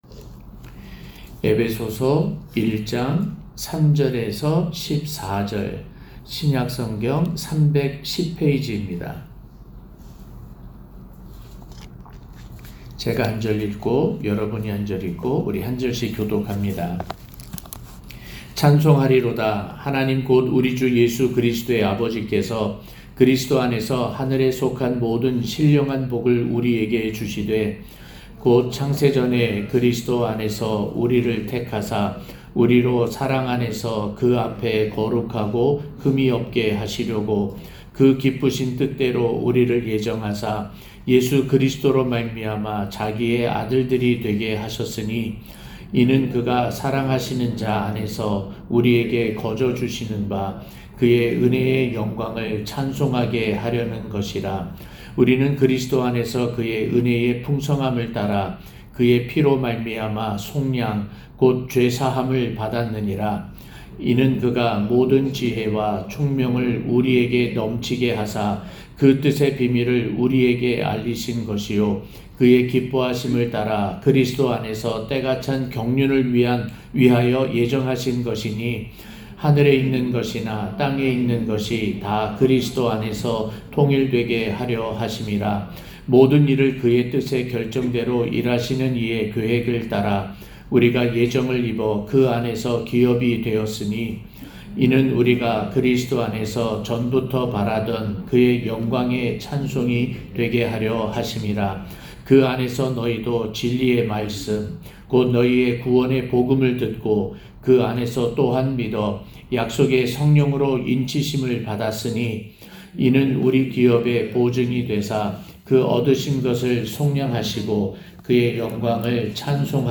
2022년 12월 31일, 특별새벽기도회 설교 (엡 1:3-14)